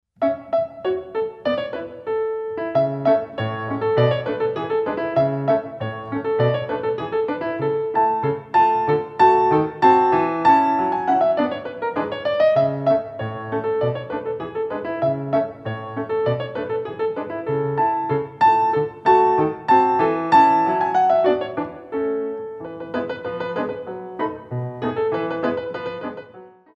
Warm-Up Allegro In 2